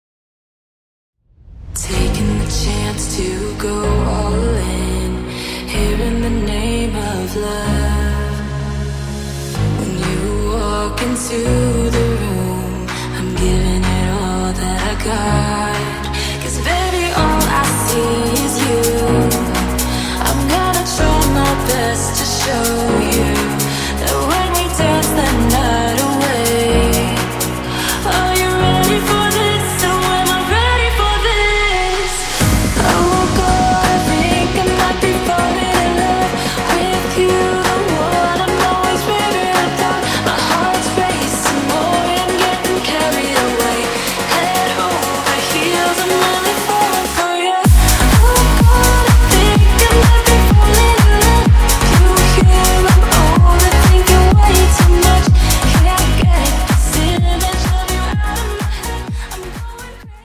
soulful melodies and enchanting vocals
deep house music single